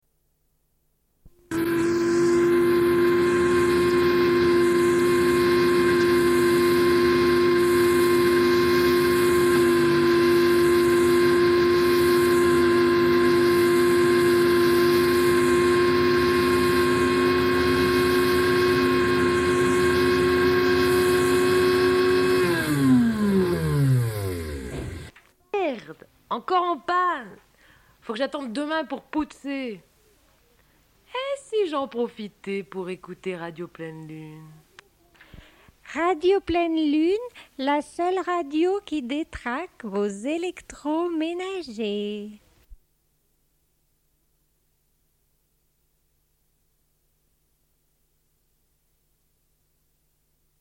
Une cassette audio, face A